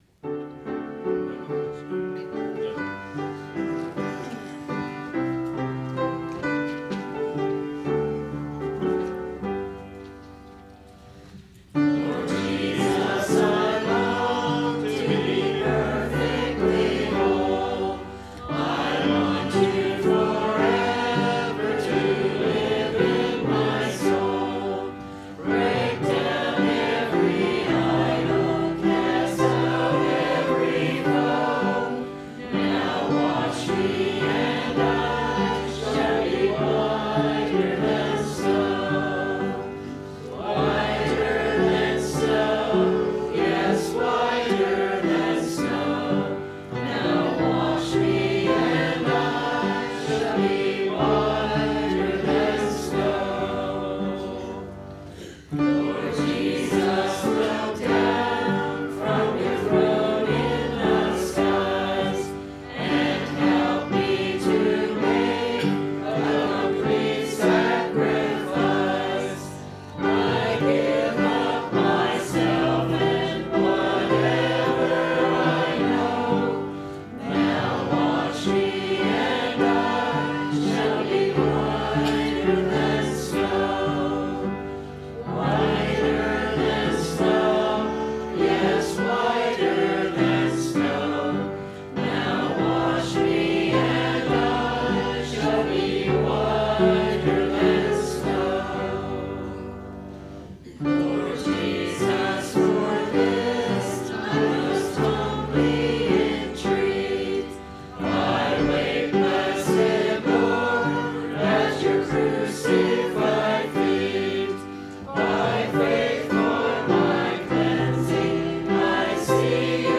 1 Thes 4:1 Share this sermon